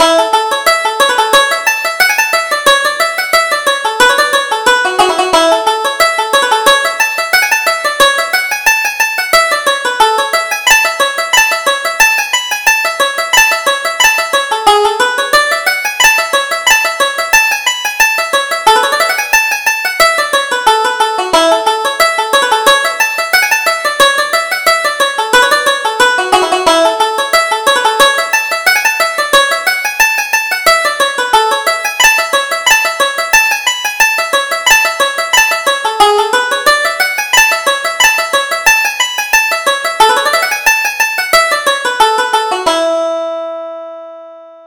Reel: Dillon's Fancy